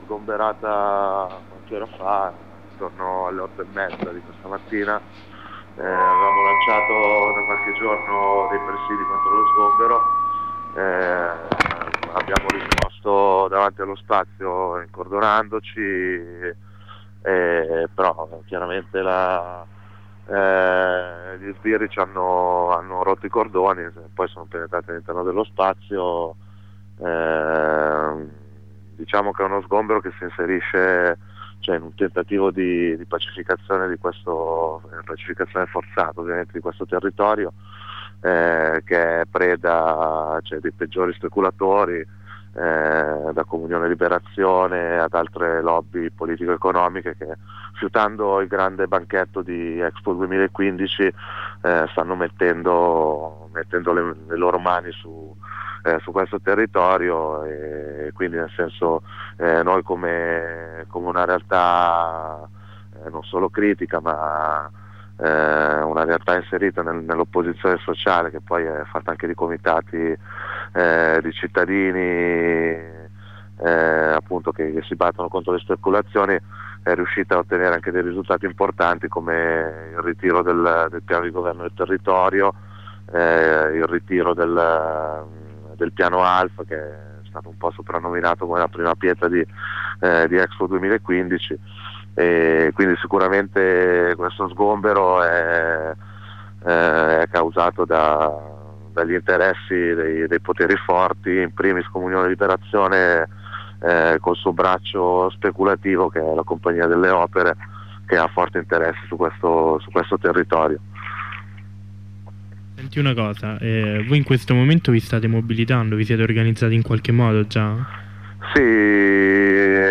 Corrispondenza con i compagni milanesi dopo lo sgombero della fornace, questa sera è previsto un corteo.